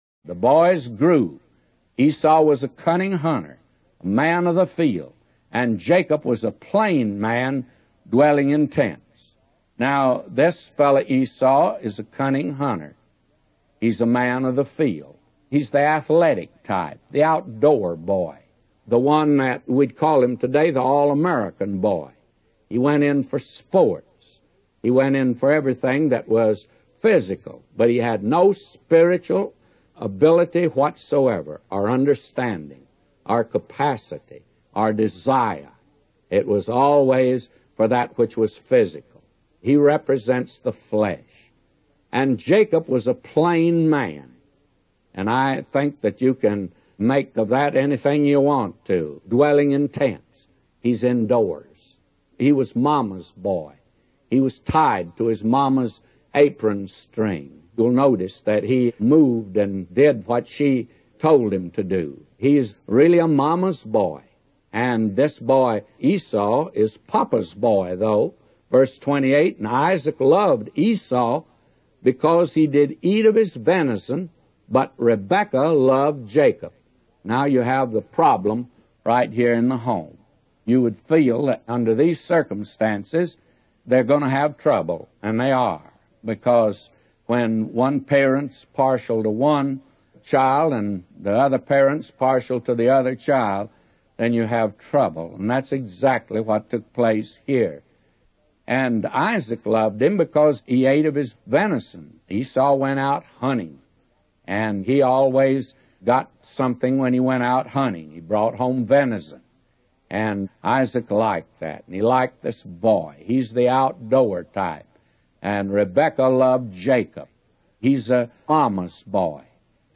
A Commentary